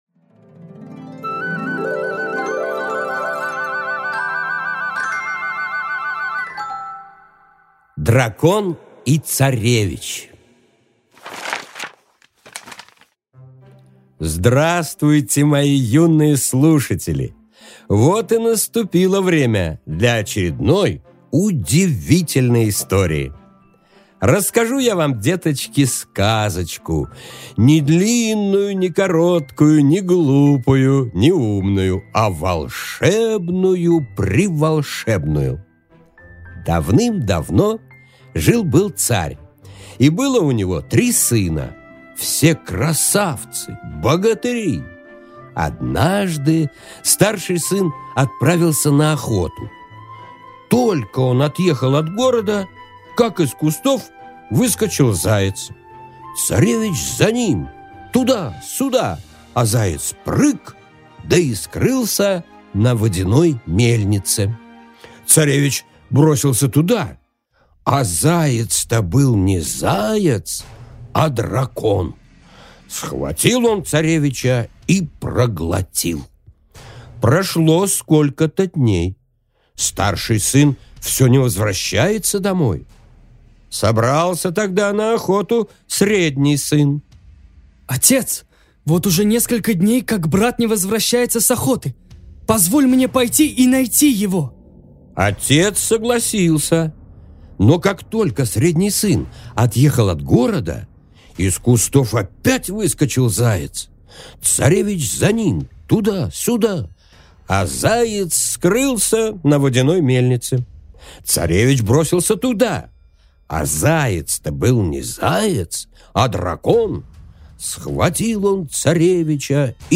Аудиокнига Дракон и царевич | Библиотека аудиокниг